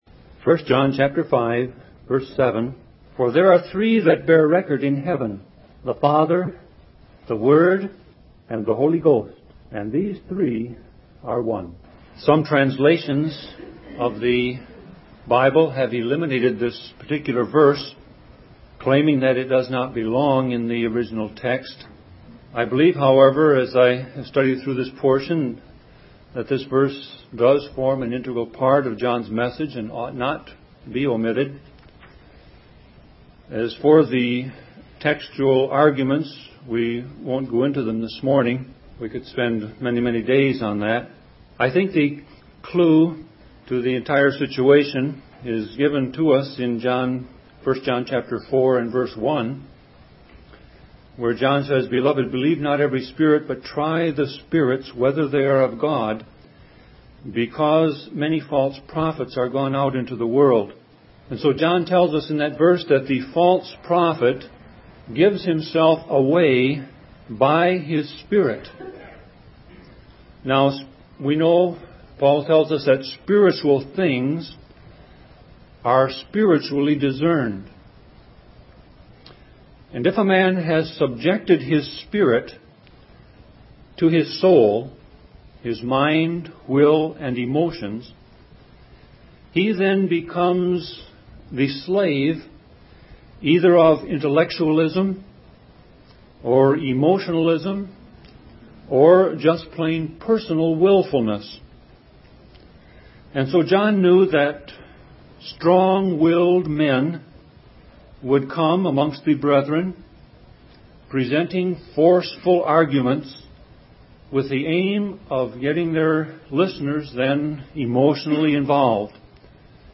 Series: Sermon Audio